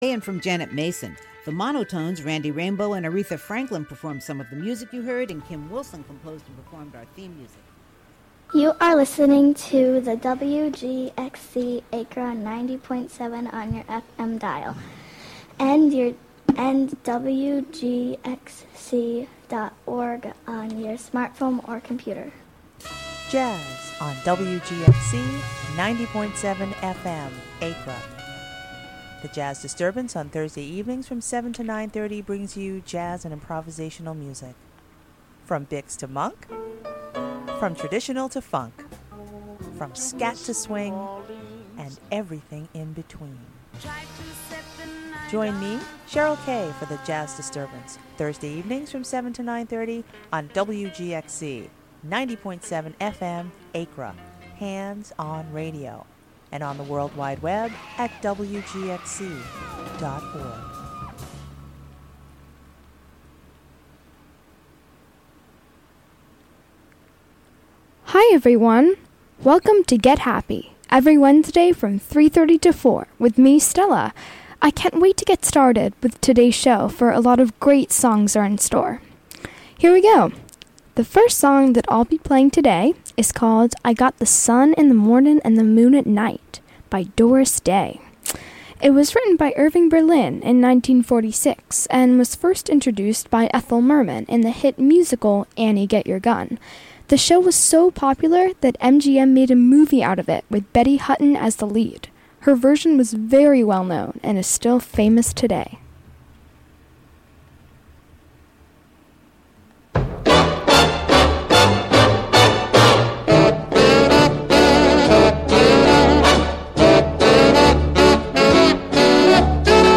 1930s and 1940s music, with an occasional foray into other genres